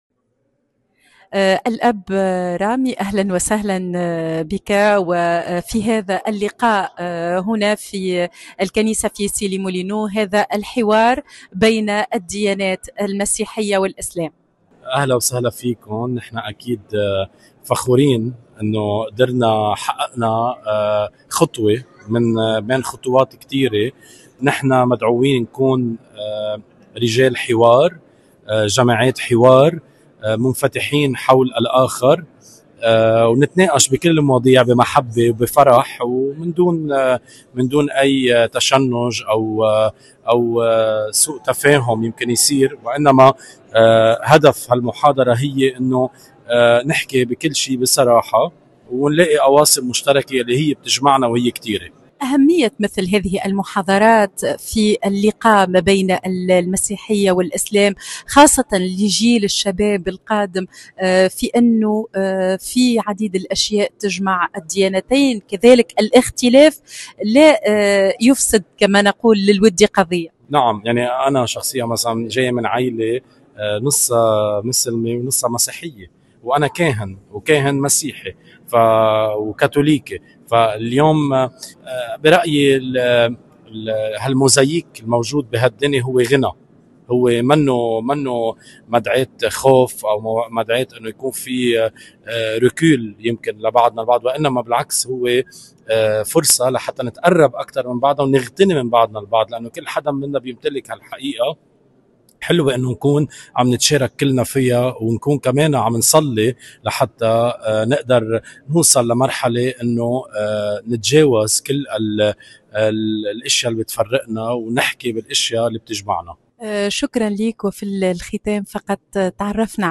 وقد تميّزت الأمسية بحضورٍ لافت لعدد كبير من المهتمين بهذا النوع من اللقاءات التي تساهم في ترسيخ ثقافة العيش المشترك وتطوير الحوار المتبادل بين المسلمين والمسيحيين.